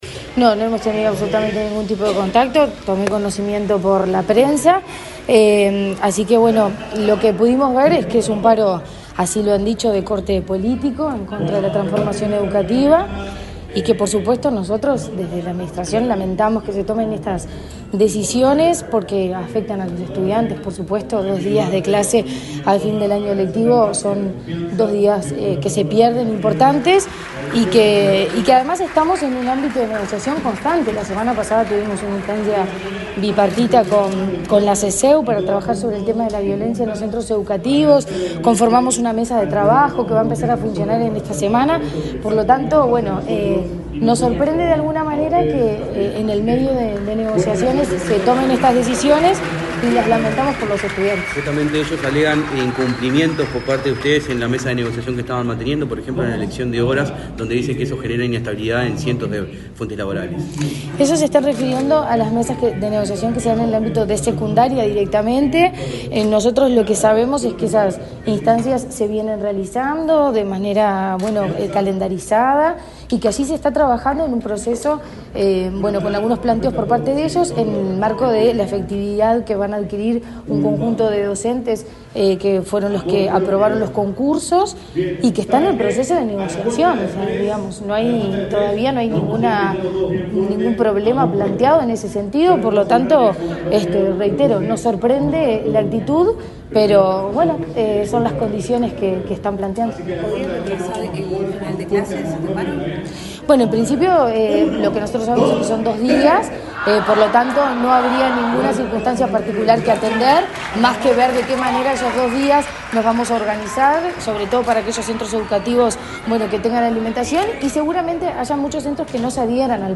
Declaraciones de la presidenta de ANEP, Virginia Cáceres
Este lunes 30 en Montevideo, la presidenta de la Administración Nacional de Educación Pública (ANEP), Virginia Cácere, dialogó con la prensa, antes de